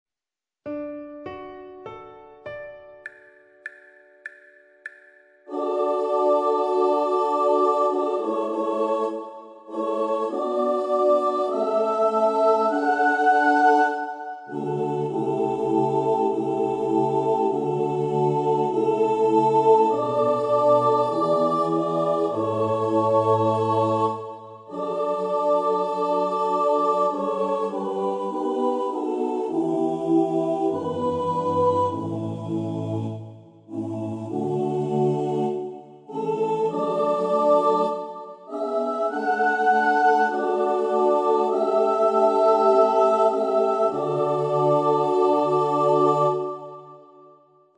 If you would like to practice up on a vocal part, here are some part recordings which may assist you.
HailPoetry_Tutti.mp3